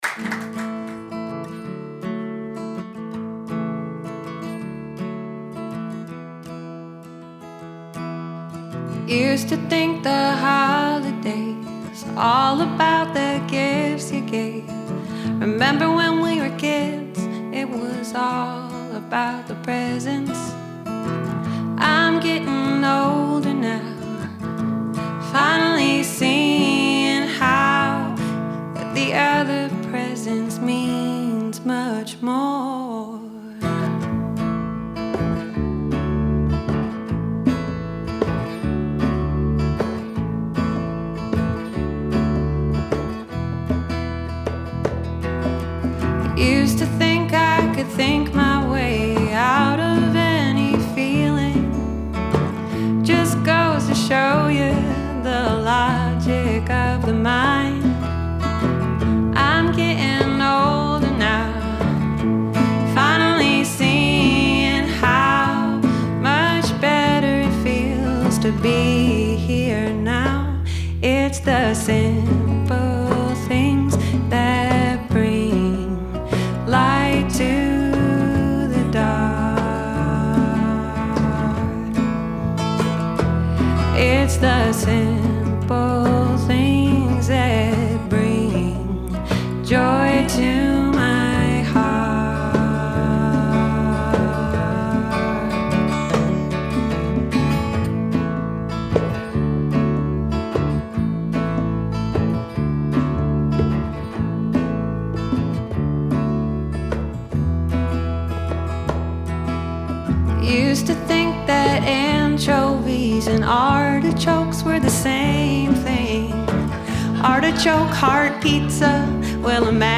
At Tank Recording Studio